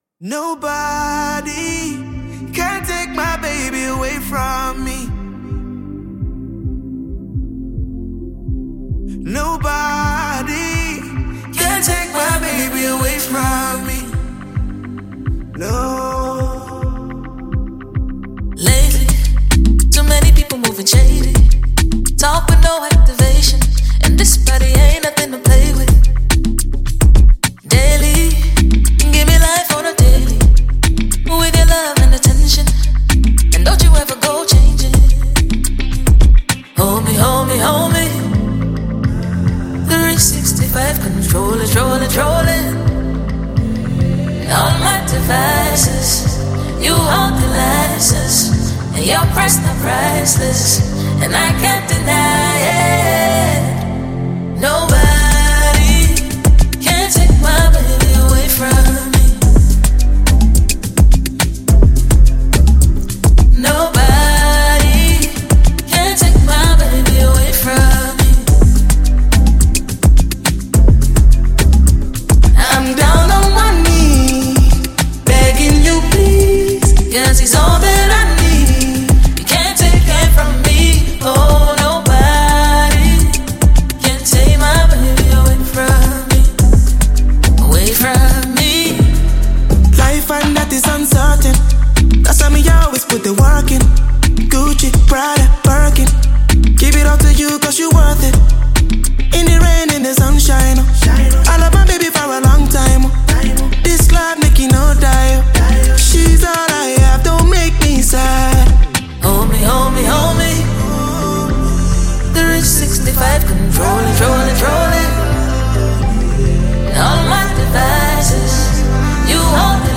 Well renowned London-based Nigerian Afro-Jazz singer
gbedu song